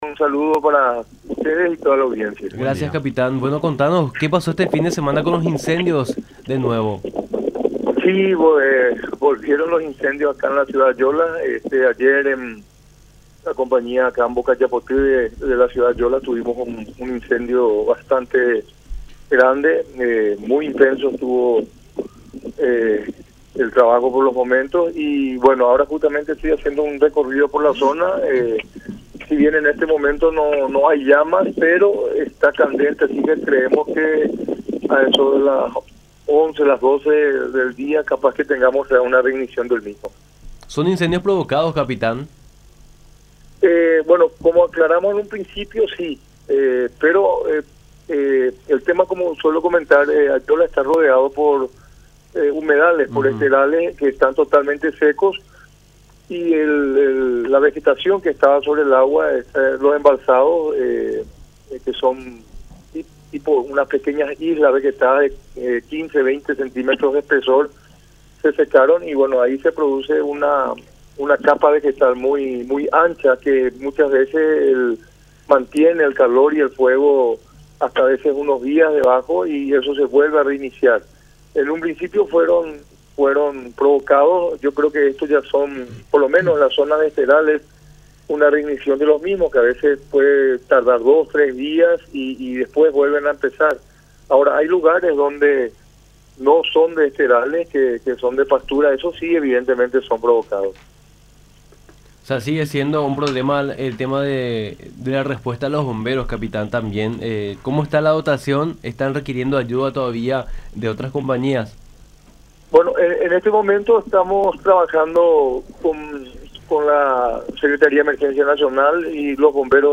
en diálogo con Nuestra Mañana a través de La Unión.